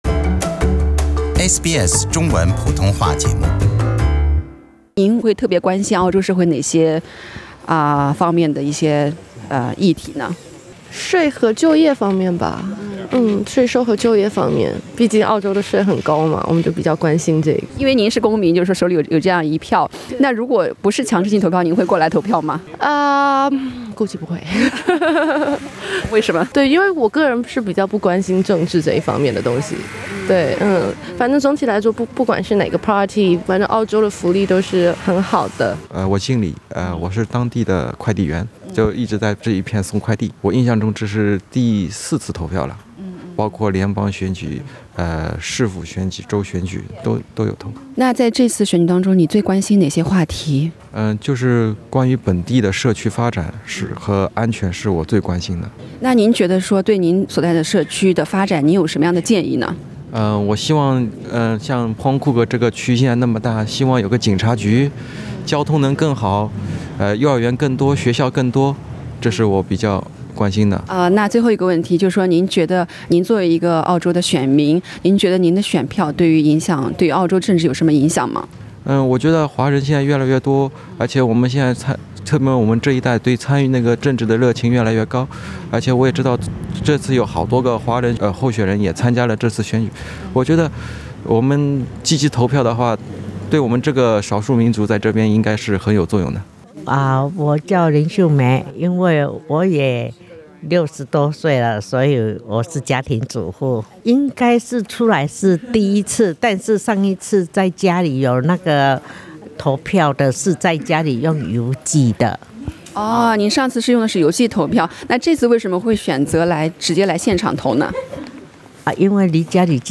SBS普通话记者在墨尔本西南部的Altona选区访问了现场等待投票的华人选民。
voting centre at Point Cook Source